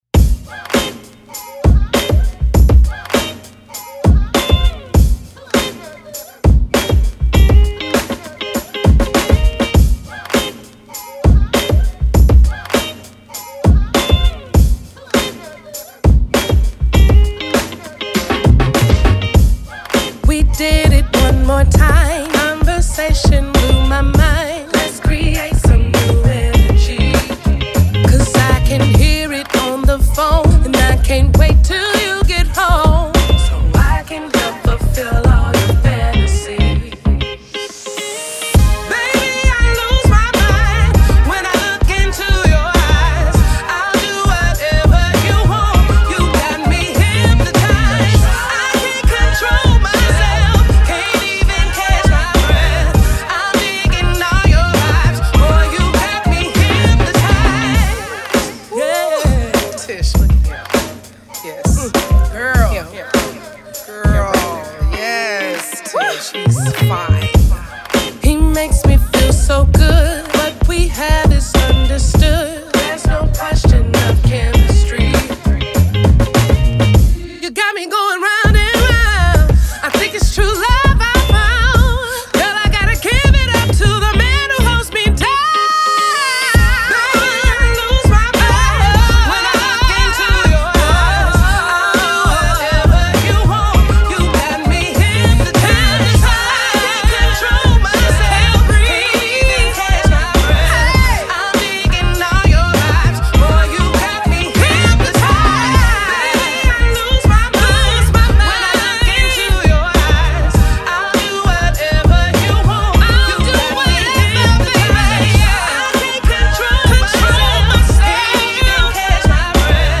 Singer | Songwriter | Nationally Featured Vocalist